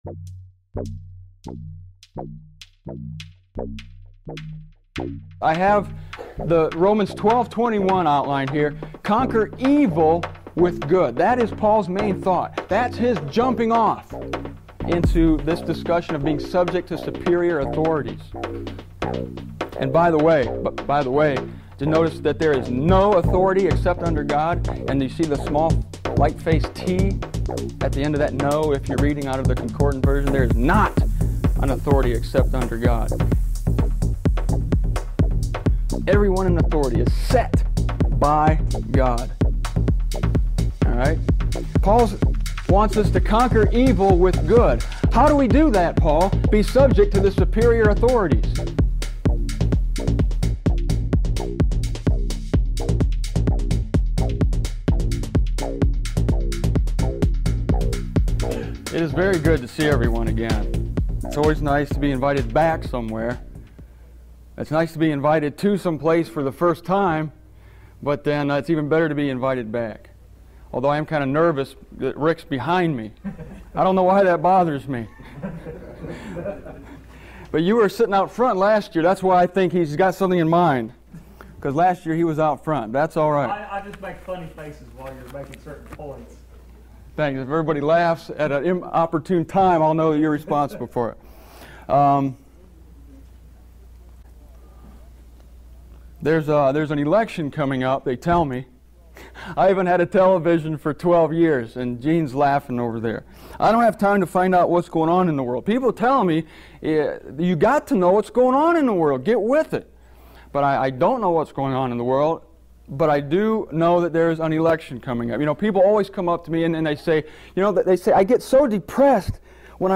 MZ/IB Archive - Sovereignty Series Government - Being Subject to Evil Authority (Part 1) Dear Fellow Believers, As soon as I heard the dog coming up the aisle, I remembered making this video.